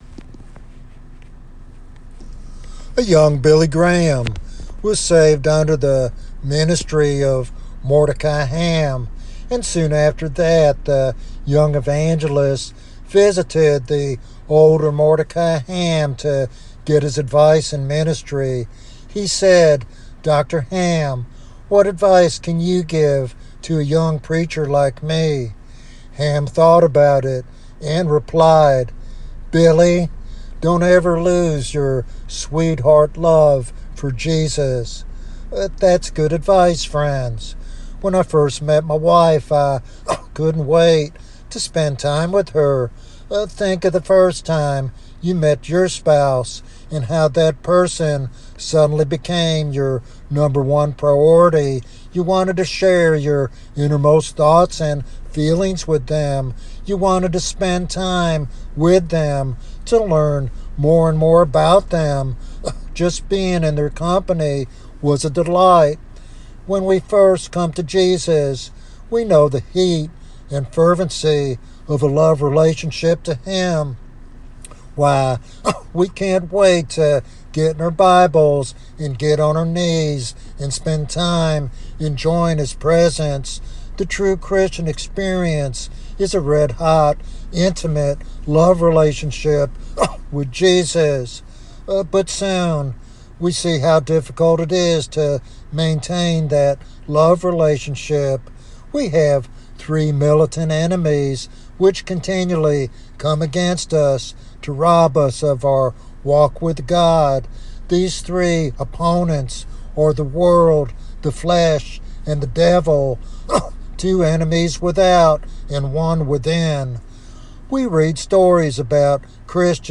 This devotional sermon encourages Christians to prioritize an intimate relationship with Christ above all else and to seek a fresh outpouring of the Holy Spirit in their lives.